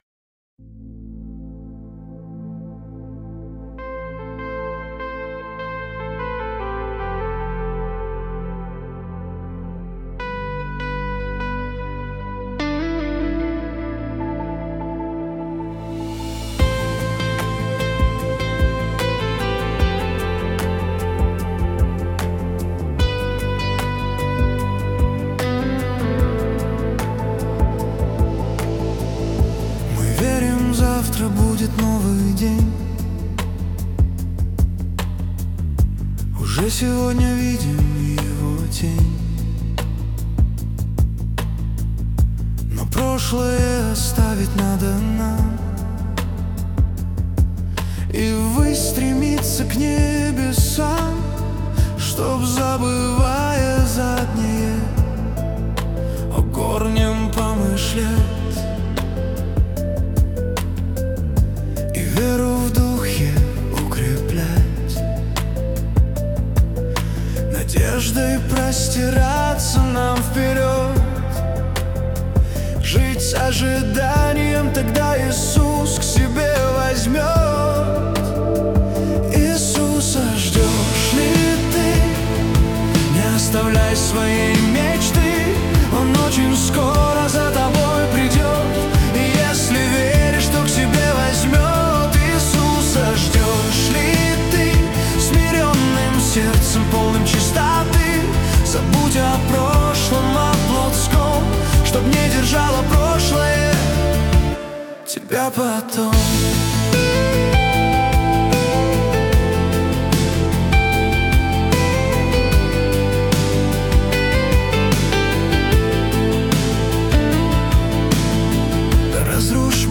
песня ai
101 просмотр 408 прослушиваний 47 скачиваний BPM: 76